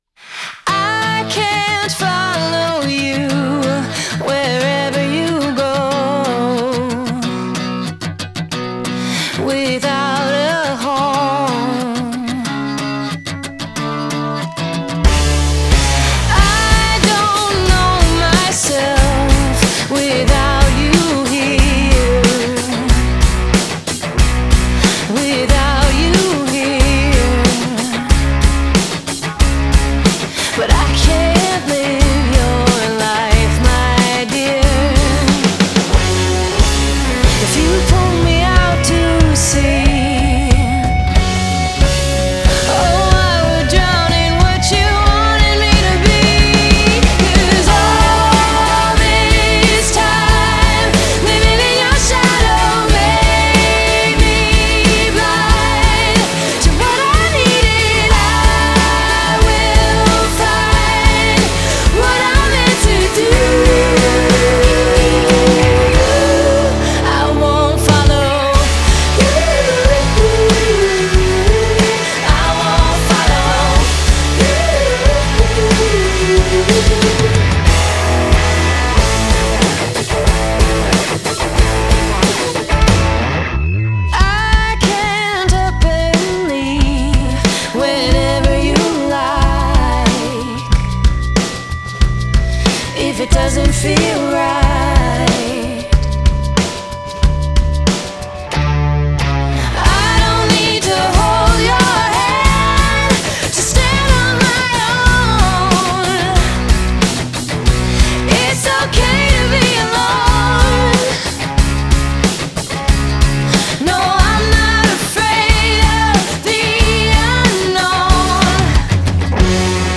Genre: Indie Pop, Alternative